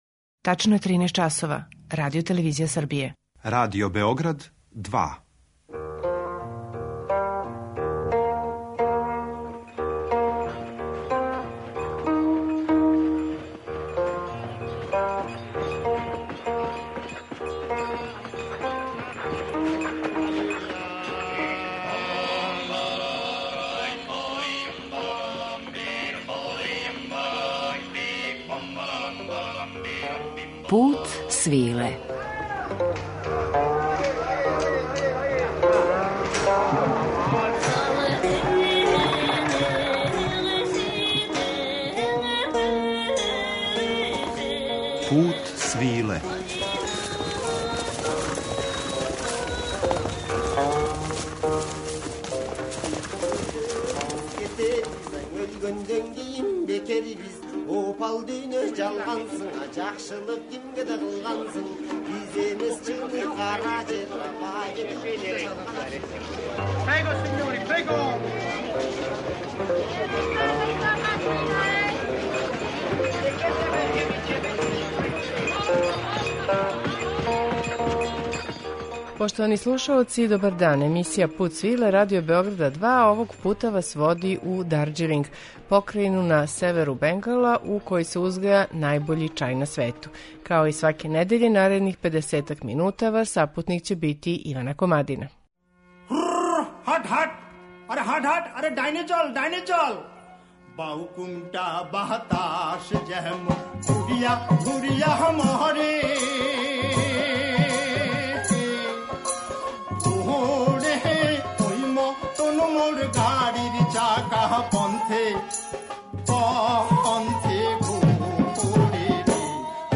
У данашњем Путу свиле , и ми ћемо се отиснути на једну чајну туру, у друштву музичара који негују три традиционална поетско-музичка стила ове покрајине: баваија, батијали и бангла кавали.